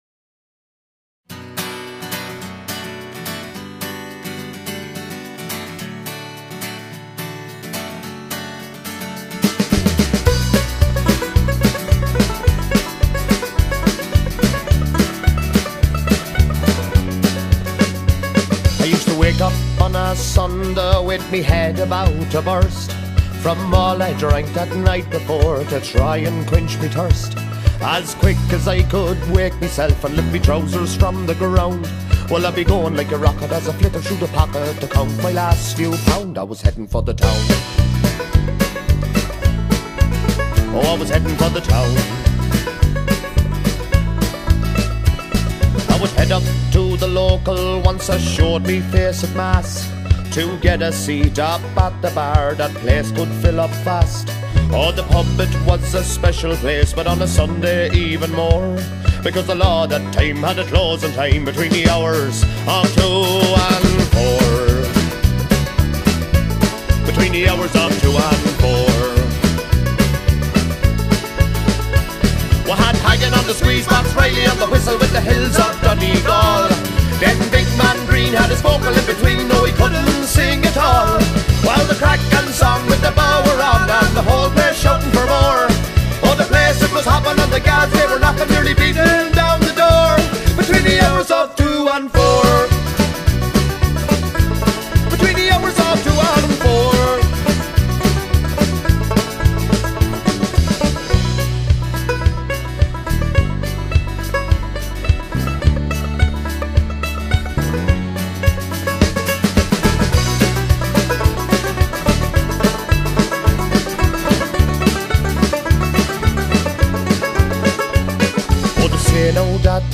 A Fusion of Irish Folk and Modern hits with a Twist of Folk
five-piece ensemble
banjo